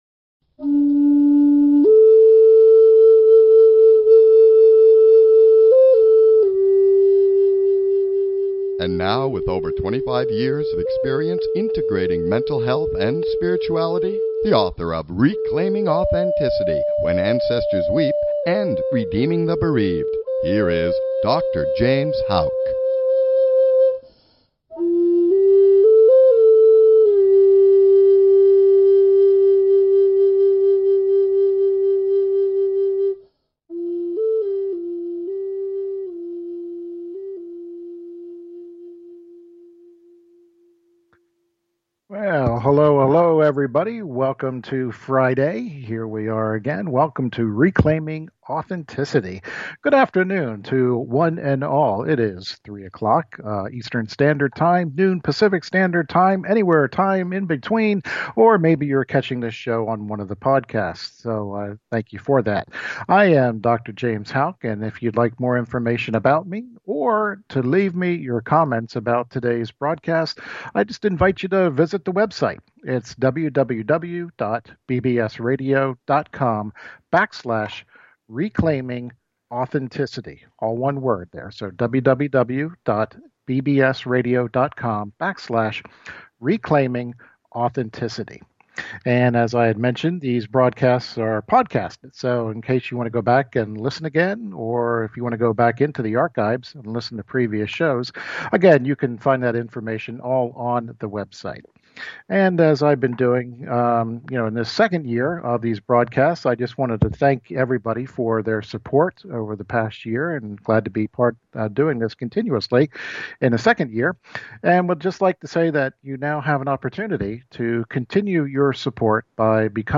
Talk Show Episode, Audio Podcast, Reclaiming Authenticity and Topic: The Doctor of Discovery! The Doctrine of Discovery! How did we get here and where do we go from here! on , show guests , about The Doctor of Discovery,The Doctrine of Discovery,The Dead Talk,The soul can never be silenced, categorized as Education,Health & Lifestyle,History,Love & Relationships,Philosophy,Psychology,Self Help,Society and Culture,Spiritual